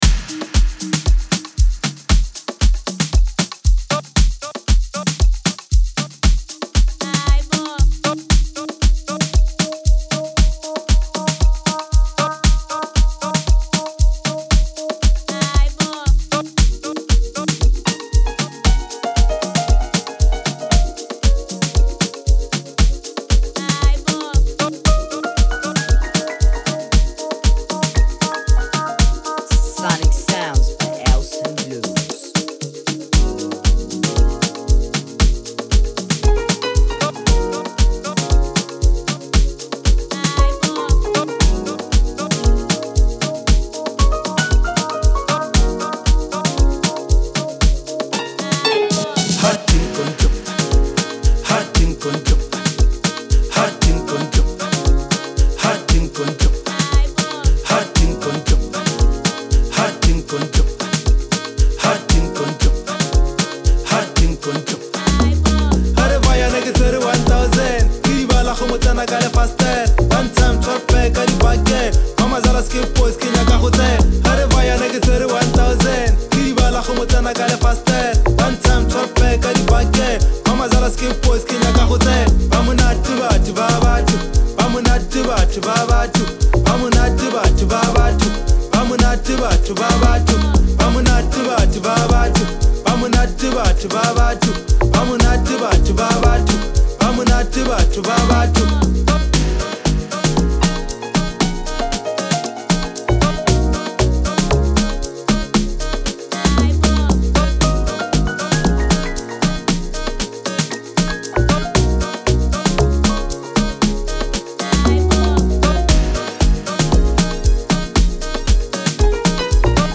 dance hall